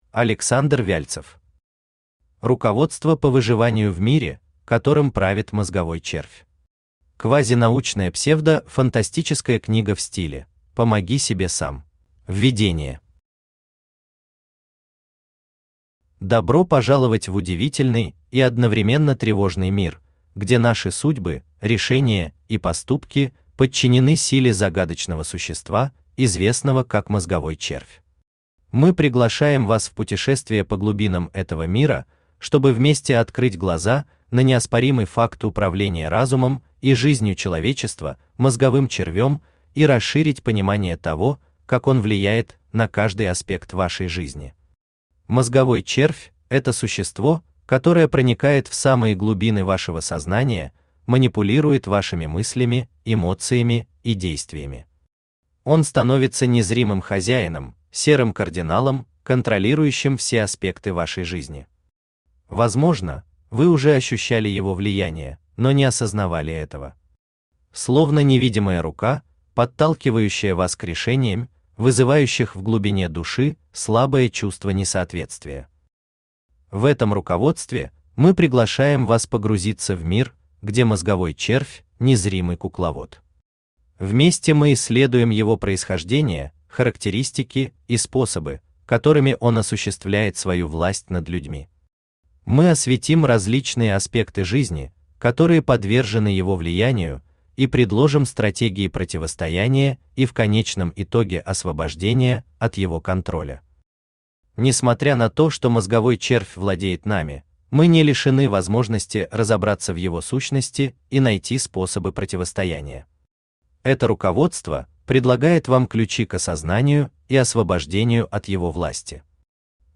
Аудиокнига Руководство по выживанию в мире, которым правит мозговой червь.
Квазинаучная псевдо-фантастическая книга в стиле: «Помоги себе сам» Автор Александр Вяльцев Читает аудиокнигу Авточтец ЛитРес.